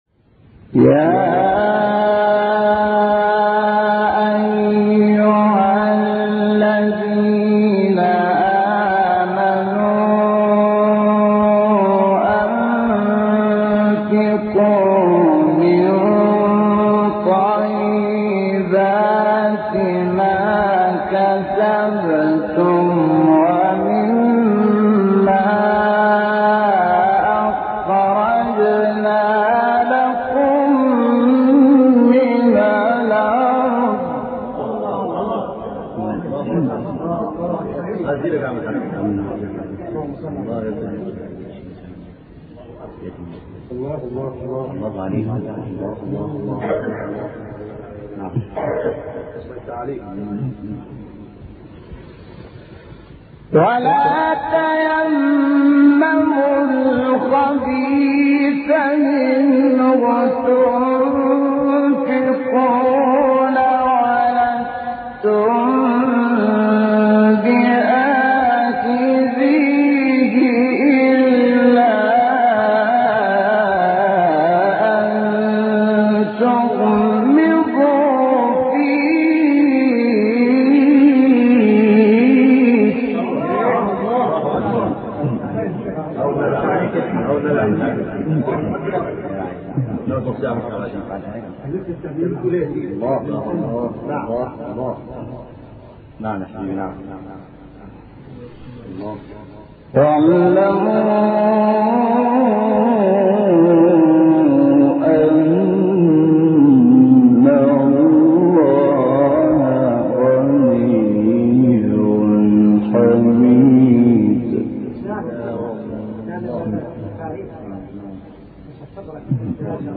سوره : بقره آیه: 267-270 استاد : شحات محمد انور مقام : رست قبلی بعدی